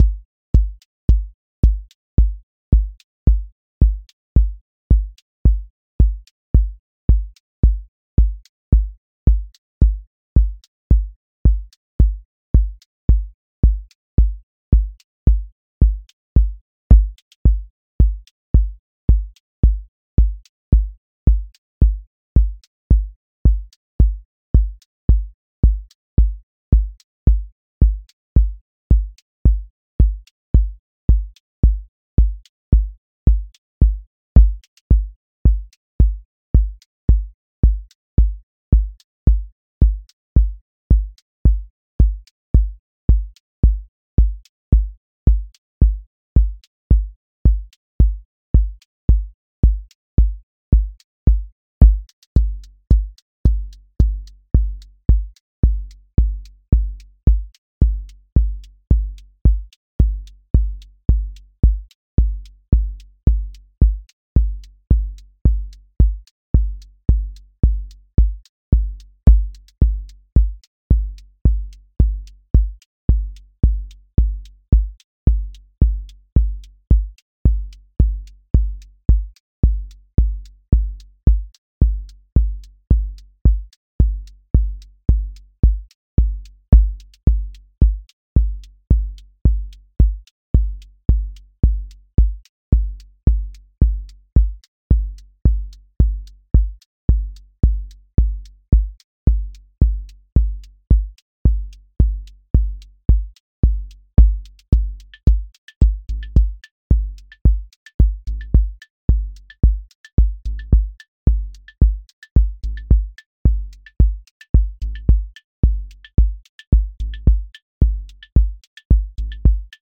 QA Listening Test trance Template: four_on_floor
120-second house song with grounded sub, counter motion, a bridge lift, and a clear return
• voice_kick_808
• voice_hat_rimshot
• voice_sub_pulse
• motion_drift_slow